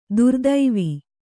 ♪ durdaivi